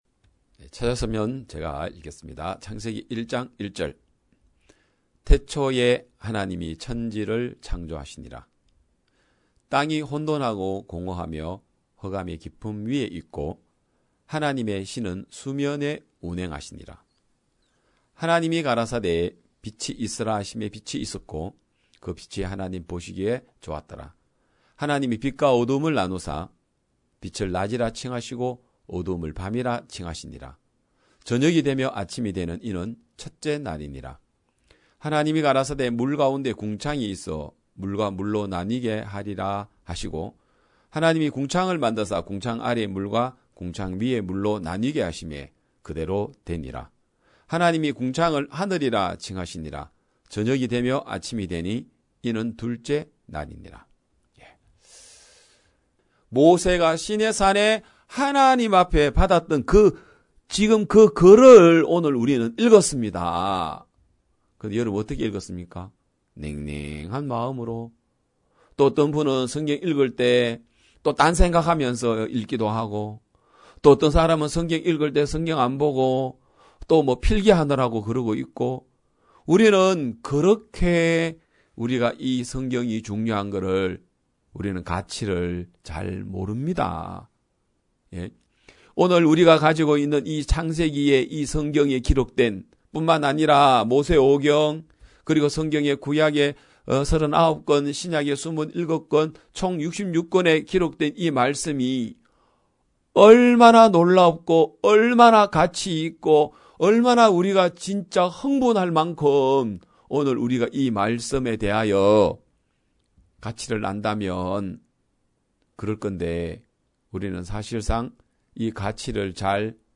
2022년 1월 9일 기쁜소식양천교회 주일오전예배
성도들이 모두 교회에 모여 말씀을 듣는 주일 예배의 설교는, 한 주간 우리 마음을 채웠던 생각을 내려두고 하나님의 말씀으로 가득 채우는 시간입니다.